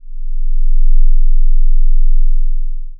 We can think of an ADSR envelope as a time-varying amplitude filter that shapes the amplitude of another signal.
newSound = env * sixtyHertz